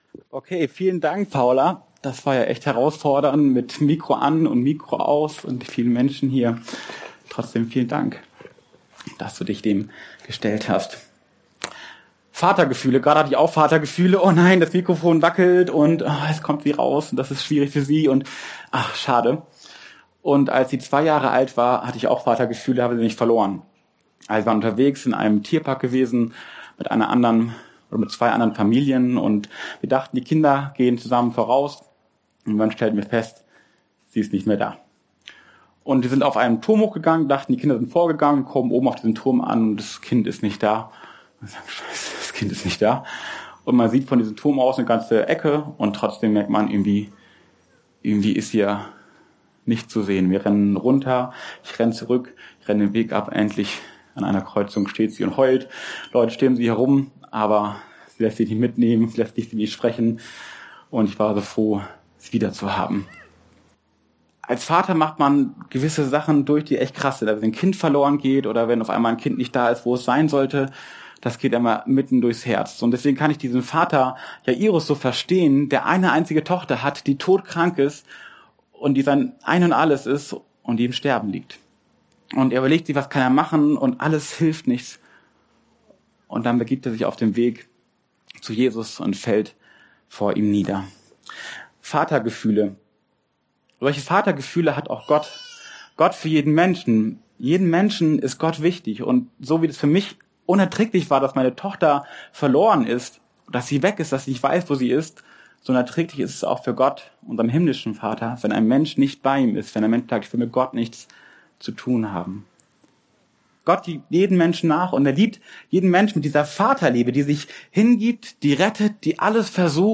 Aufgrund eines technischen Problems, wurden leider nur die ersten 10 Minuten der Predigt aufgenommen.
Dienstart: Predigt